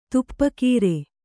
♪ tuppa kīre